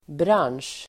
Uttal: [bran:sj]